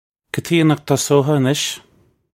Pronunciation for how to say
Ka tee-ya nukh duss-oh-ha a-nish? (U)
This is an approximate phonetic pronunciation of the phrase.